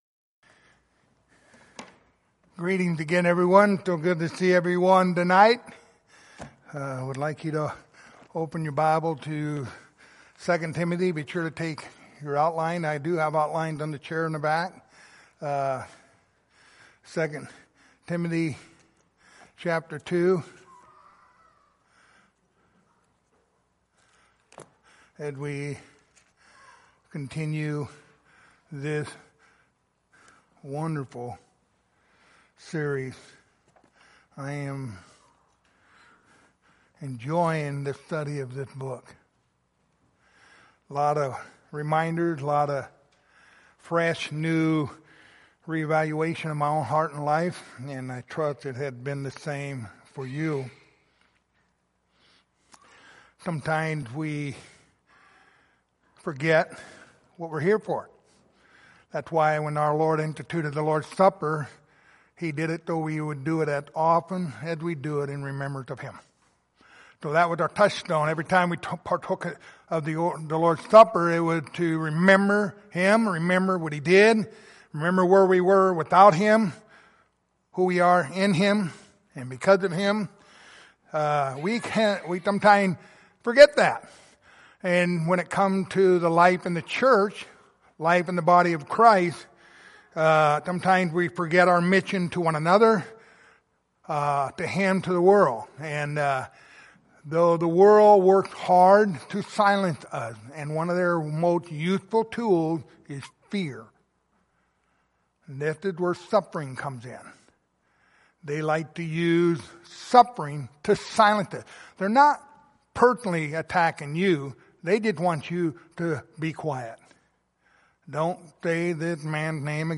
Passage: 2 Timothy 2:8-10 Service Type: Sunday Evening